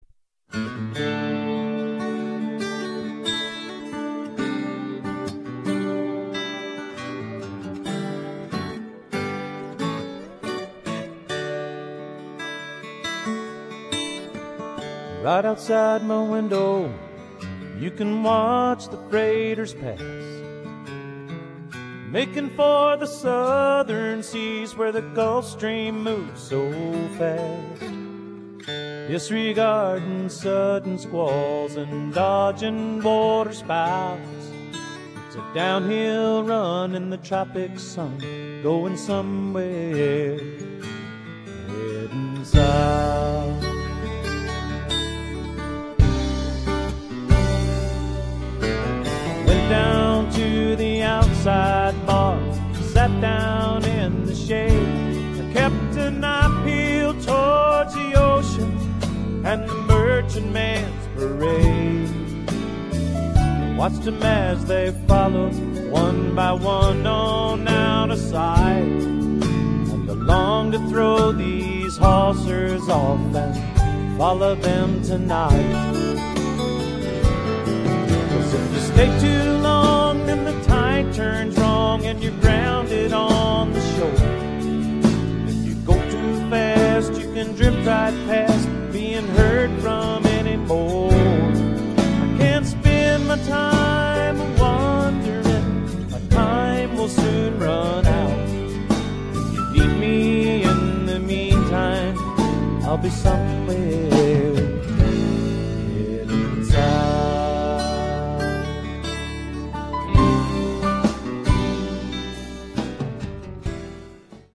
island music